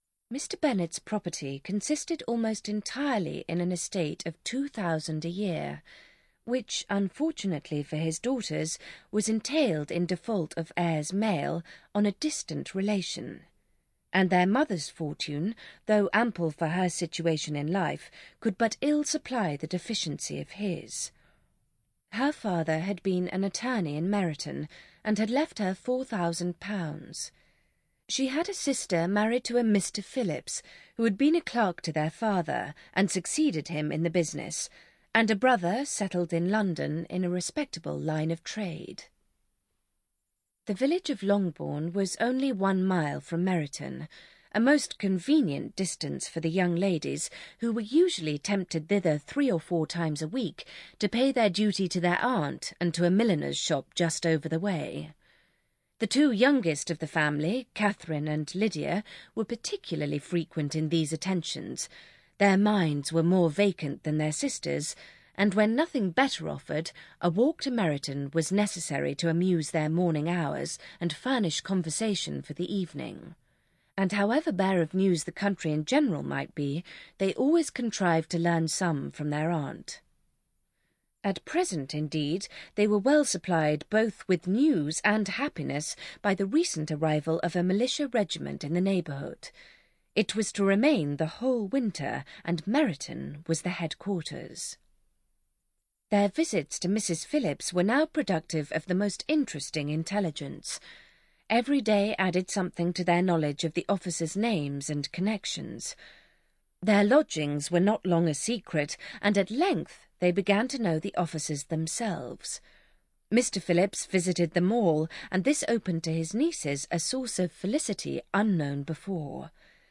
Pride and Prejudice (EN) audiokniha
Ukázka z knihy